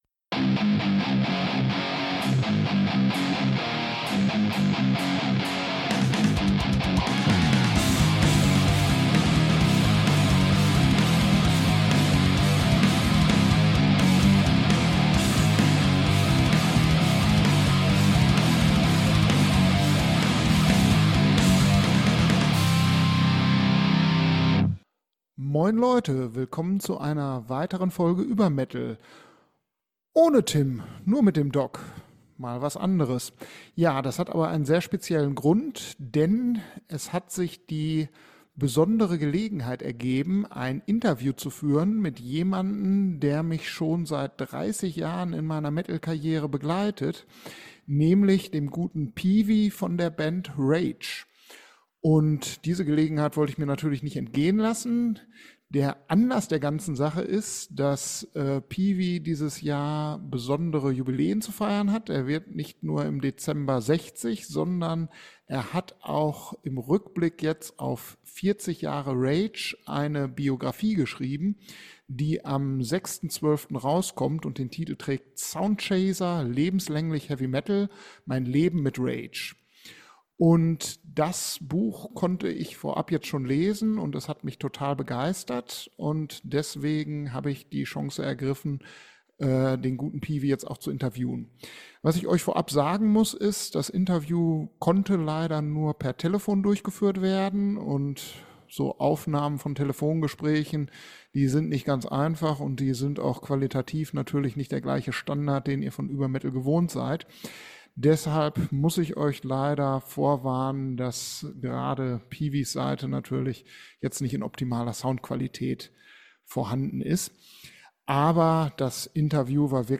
*Aufgrund von Schwierigkeiten mit der Aufnahme ist die Soundqualität leider nicht so gut wie sonst.